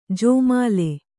♪ jōmāle